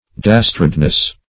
Dastardness \Das"tard*ness\, n.